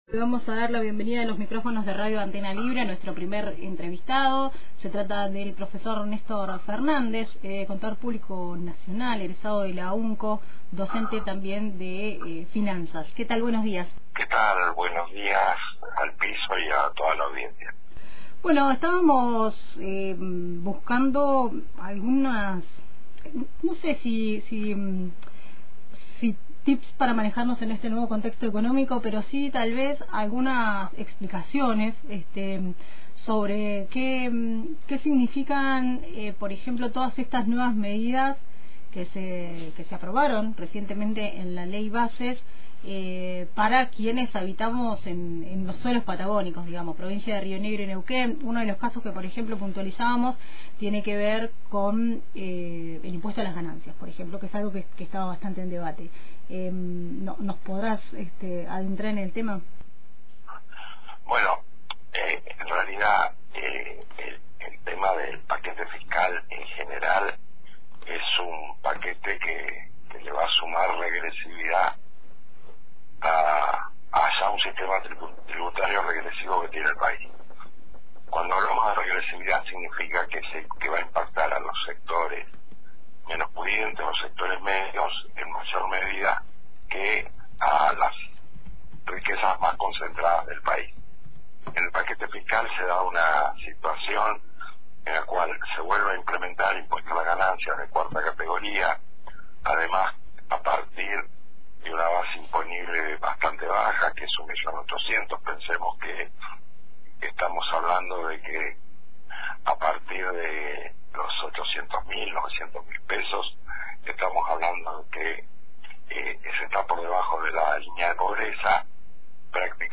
Escucha la entrevista completa acá: